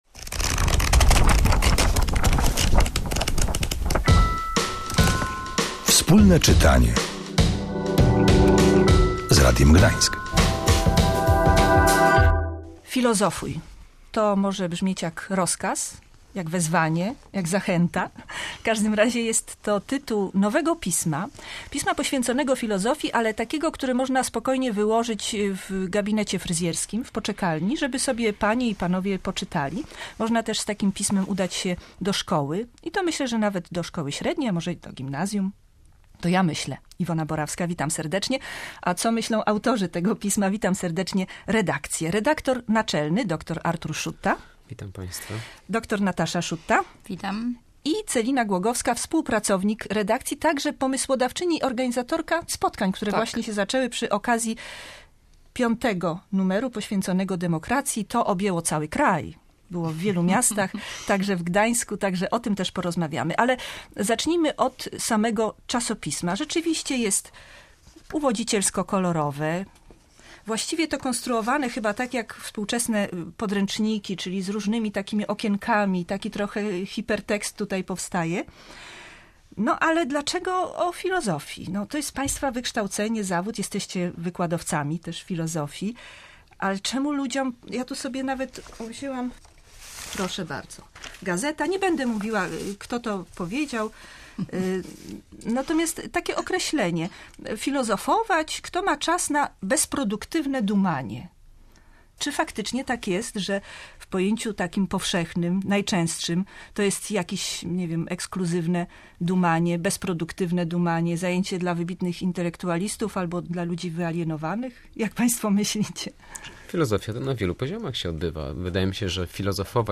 W studiu gośćmi byli: